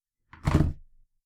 Impacts
flip.wav